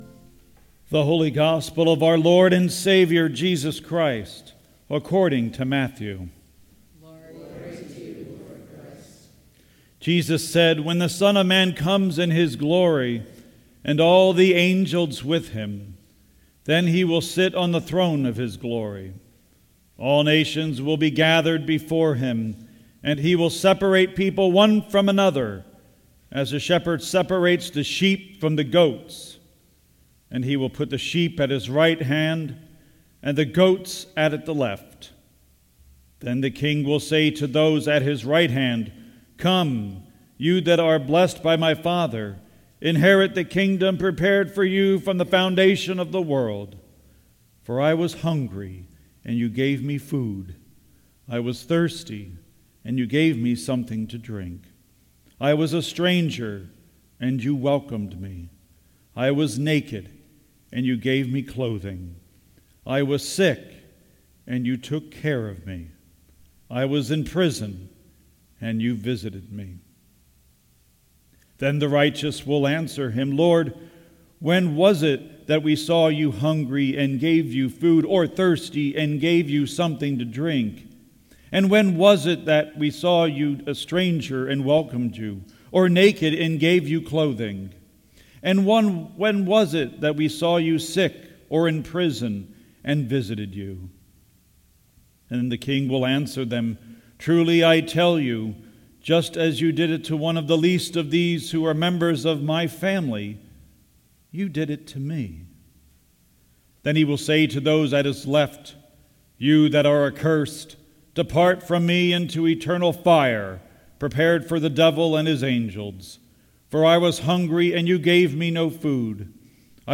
WEEKLY GOSPEL AND SERMON, December 3, 2017 - St. Andrew's Episcopal Church